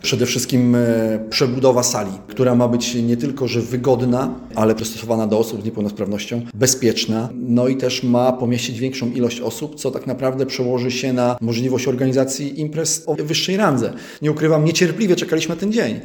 Kluczową, jak mówi burmistrz Michał Wiatr, będzie modernizacja i rozbudowa Nowogardzkiego Domu Kultury.